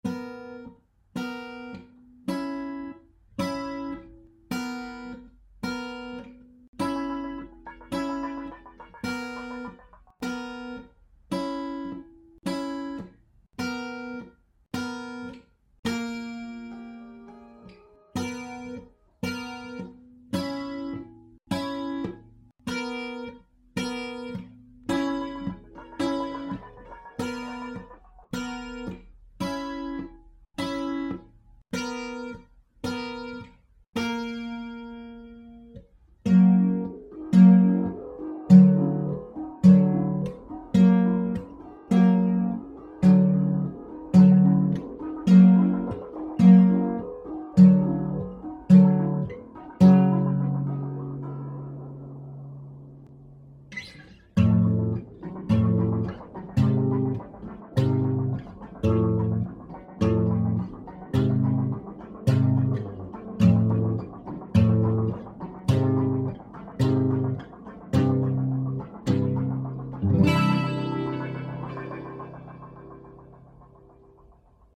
A little experiment involving some simple, yet unnerving, two-note guitar harmonies and some FX tomfoolery.